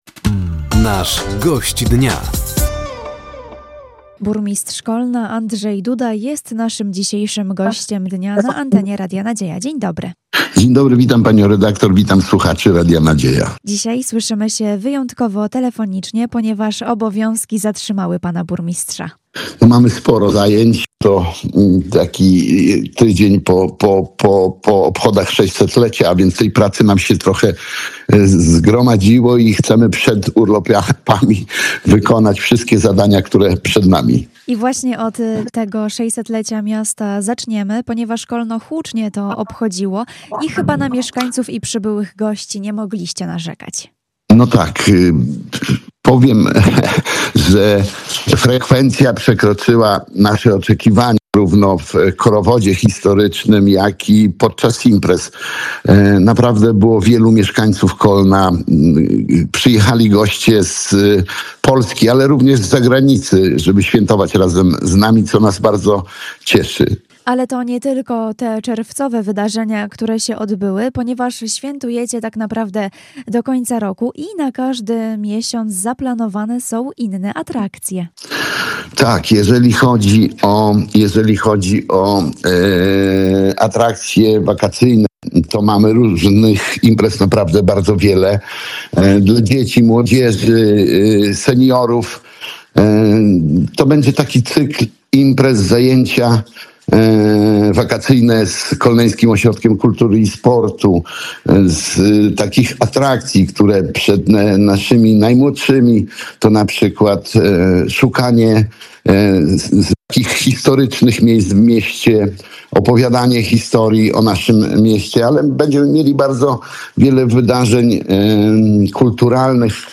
Gościem Dnia Radia Nadzieja był Andrzej Duda, burmistrz Kolna.